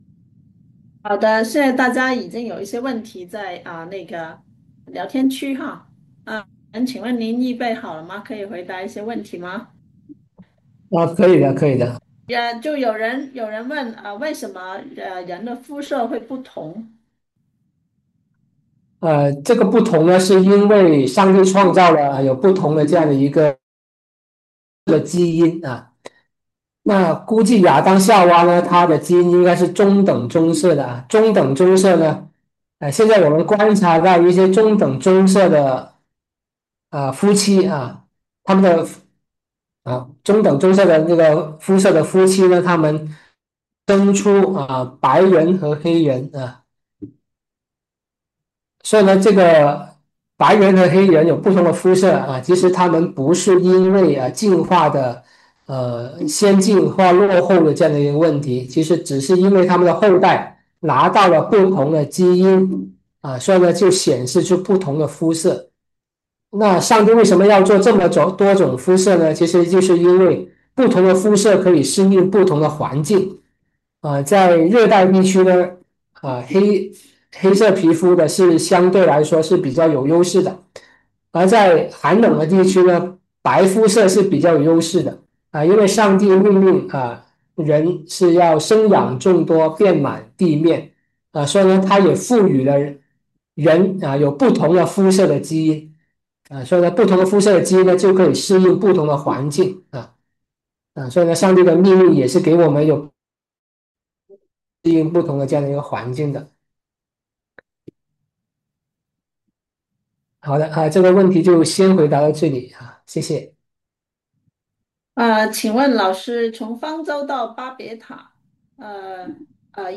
《古代高科技的起源》讲座直播回放
high-tech-qa.mp3